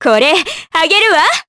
Juno-Vox_Skill1_jp.wav